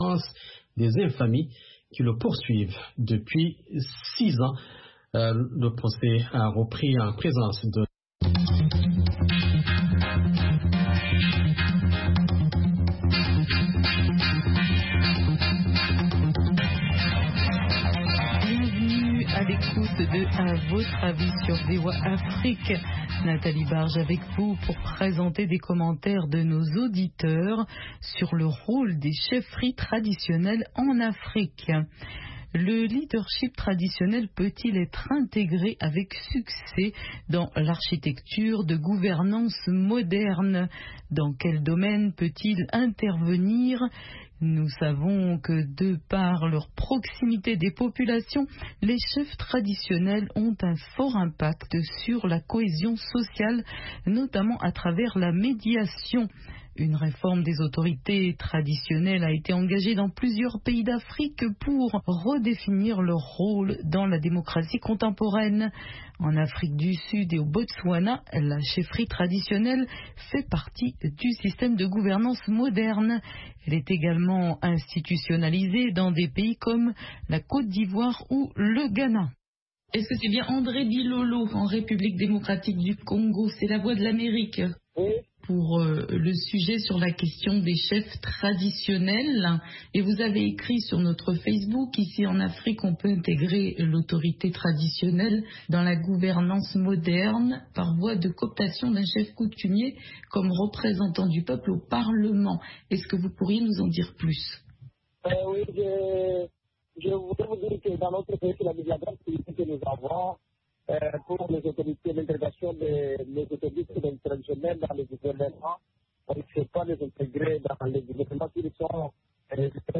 Le programme quotidien d'appel de VOA Afrique offre aux auditeurs un forum pour commenter et discuter d'un sujet donné, qu'il s'agisse d'actualités ou de grands sujets de débat.